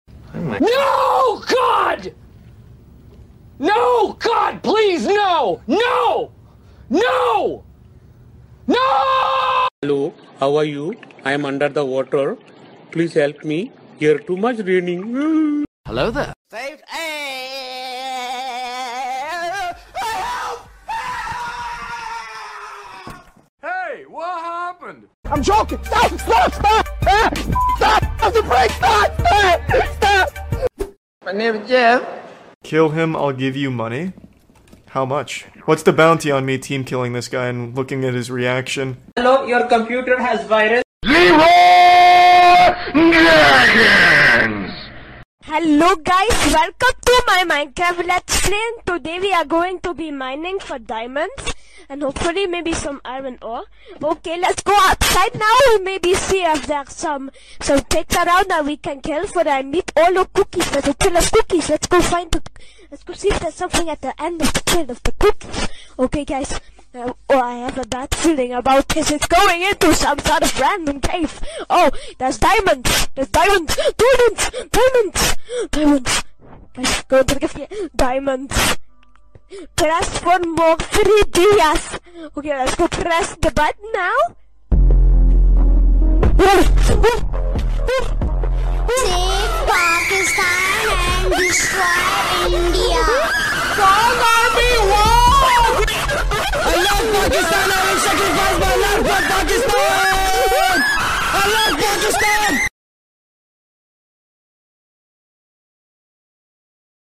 From clean swooshes and cinematic hits to meme-worthy effects and transitions, this pack is perfect for YouTube, TikTok, Instagram Reels, Shorts, vlogs, and edits.